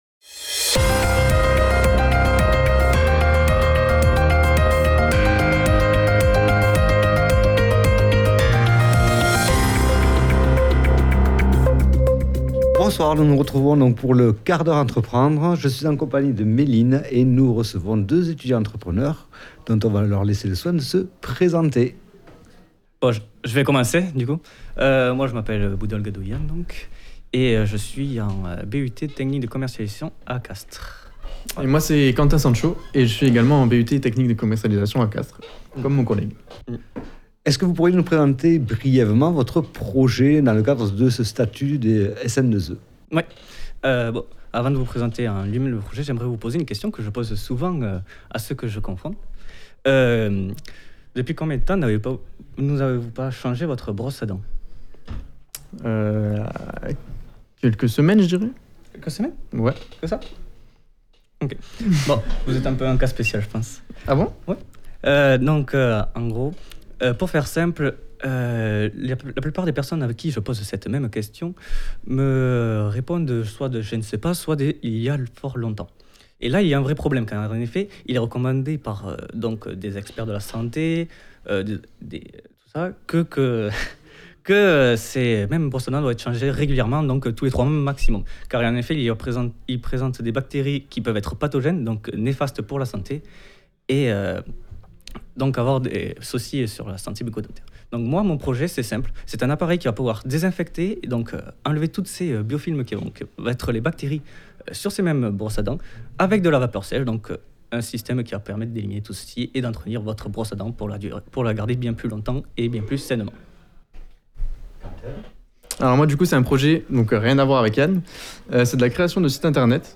Description Ce n'est pas parce que les chaises grincent que la tension est palpable.
Reportages